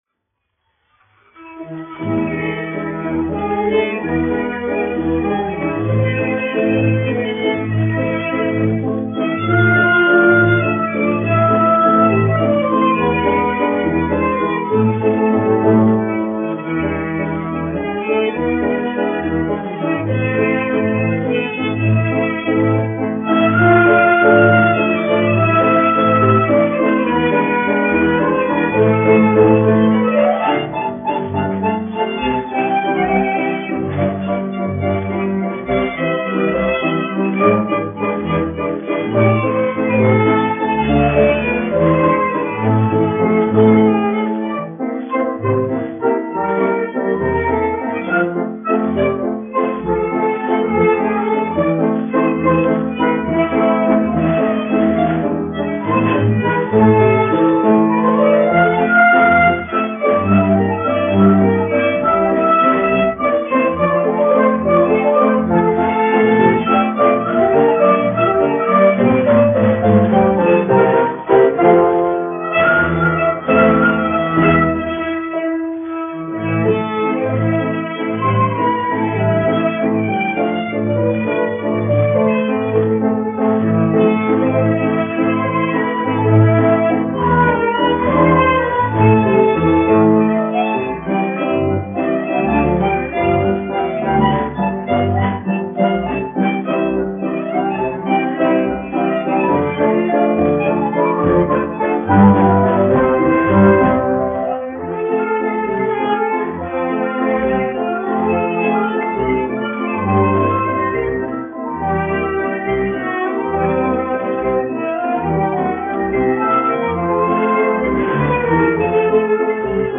Šurpfeļa zemnieku kapele, izpildītājs
1 skpl. : analogs, 78 apgr/min, mono ; 25 cm
Valši
Populārā instrumentālā mūzika
Latvijas vēsturiskie šellaka skaņuplašu ieraksti (Kolekcija)